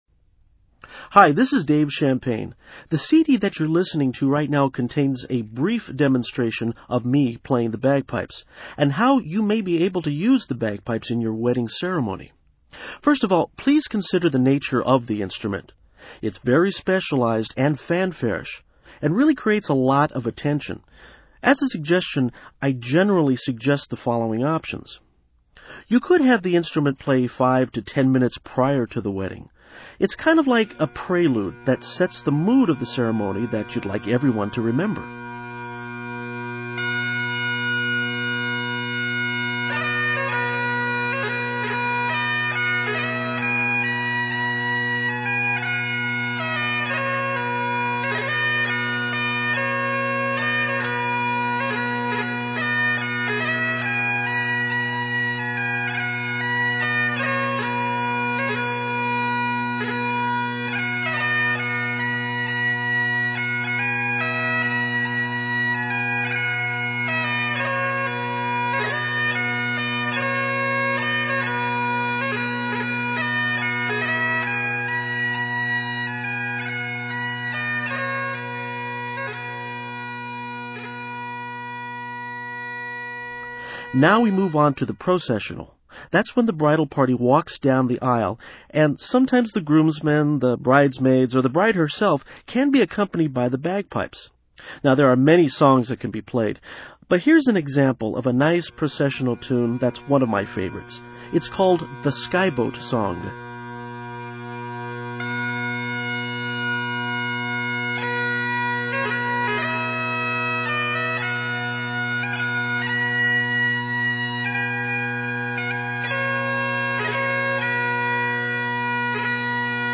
Wedding Bagpipe Demo
BagpipeDemo.mp3